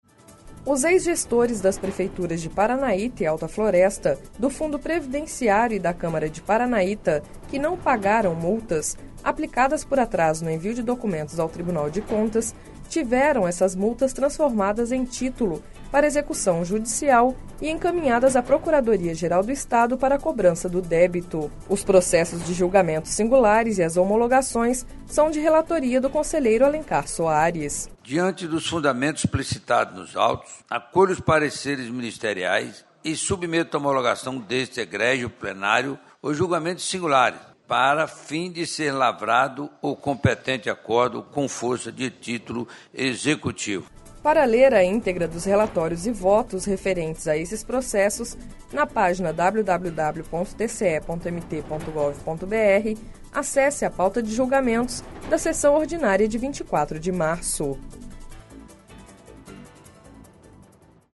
Sonora: Alencar Soares – conselheiro do TCE-MT